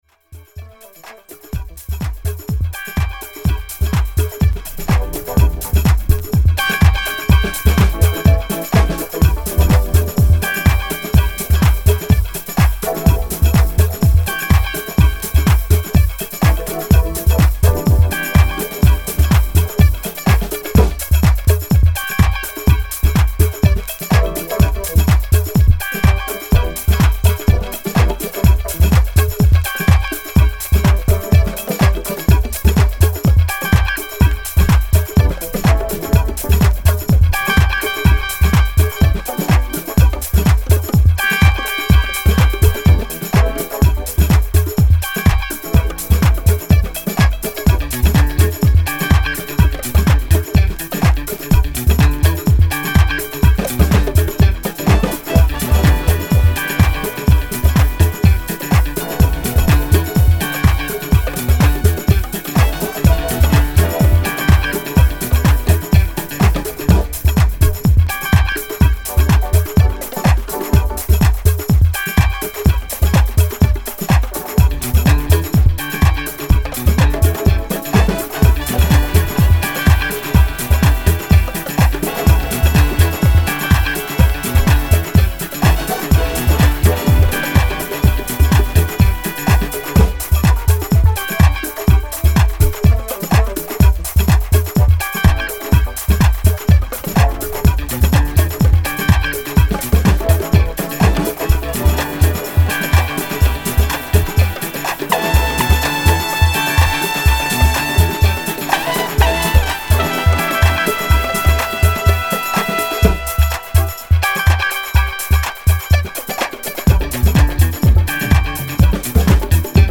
HOUSE
AFRO GROOVE